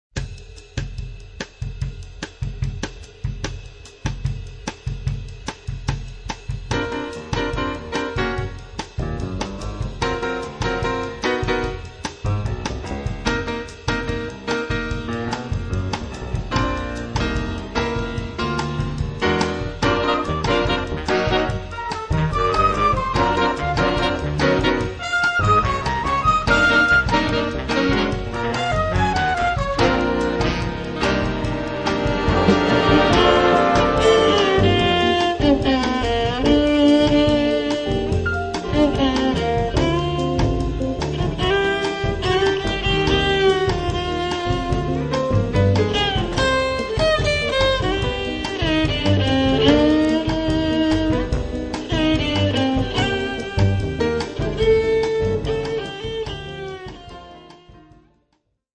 registrato dal vivo il 18 Luglio 2008